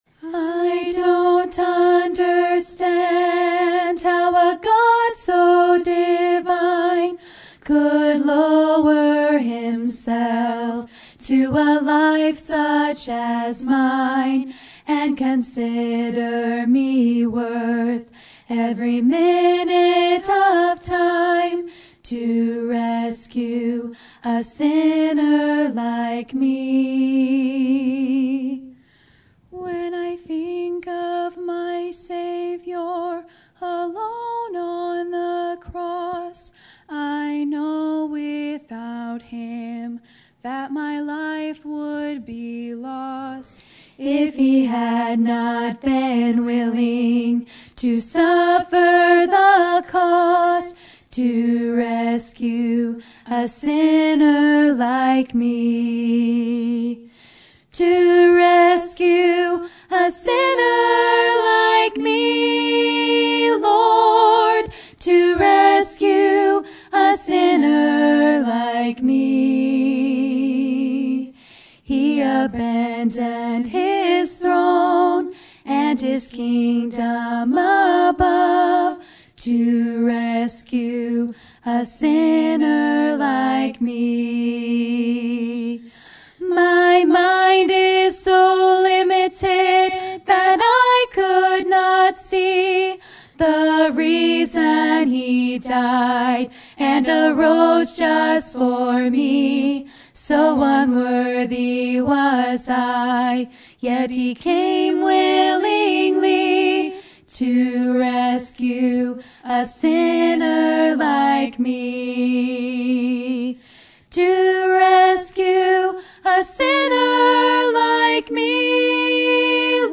Ladies Trio